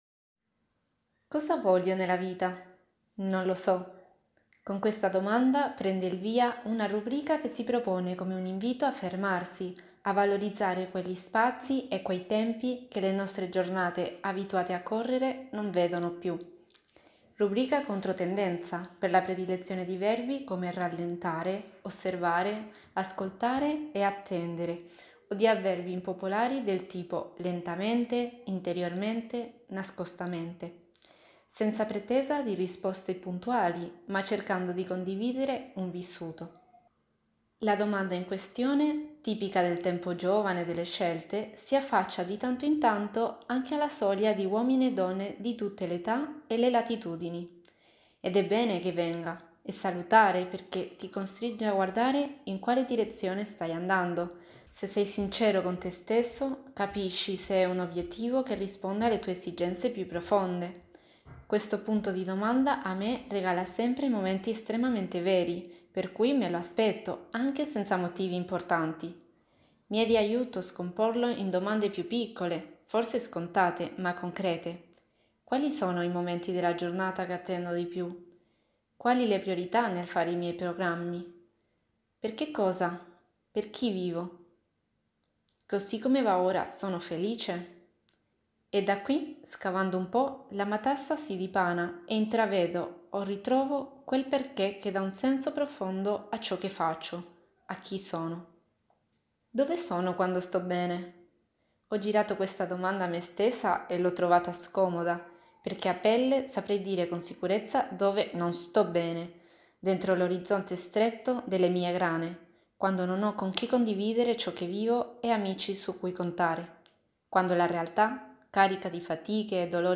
Italia > Audioletture